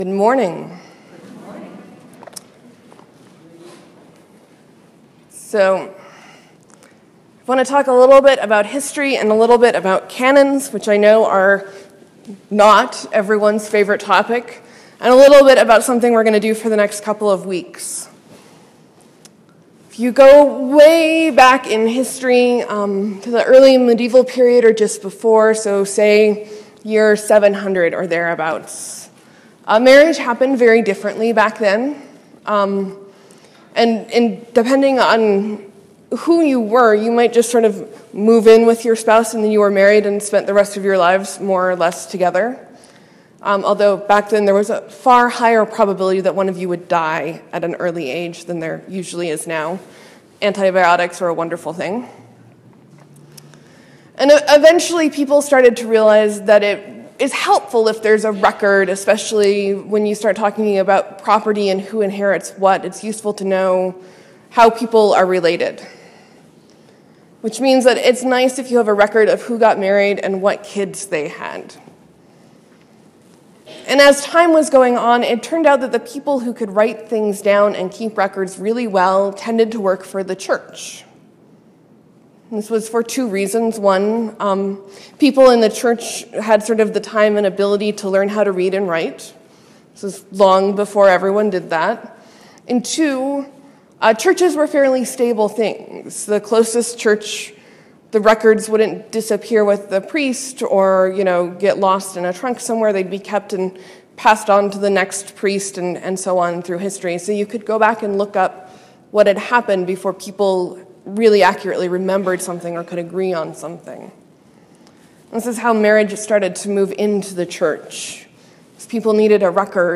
Sermon: Phoenicians, living water, and inimical seas.